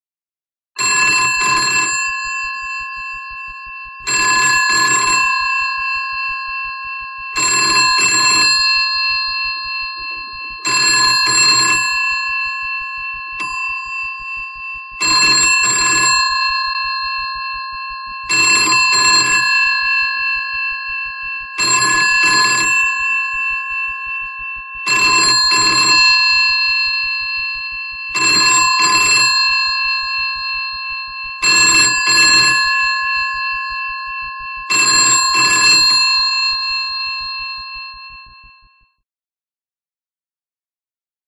Звуки звонящего телефона